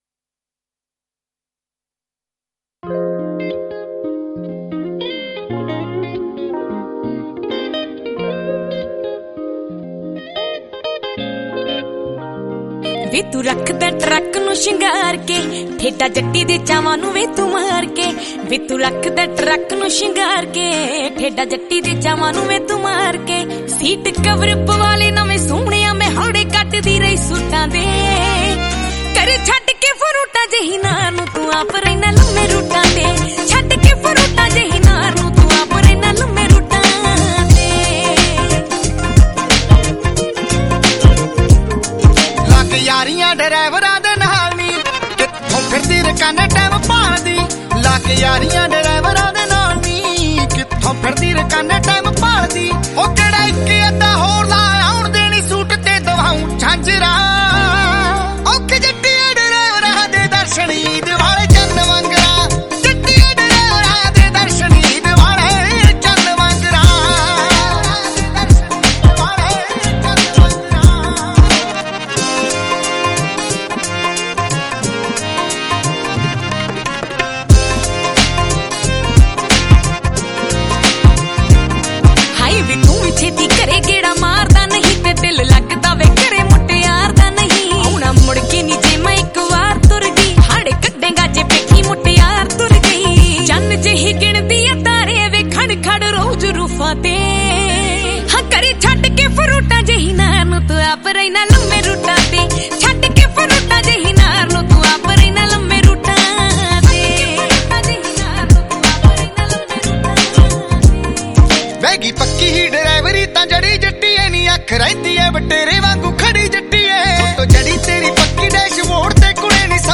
Category: Punjabi Album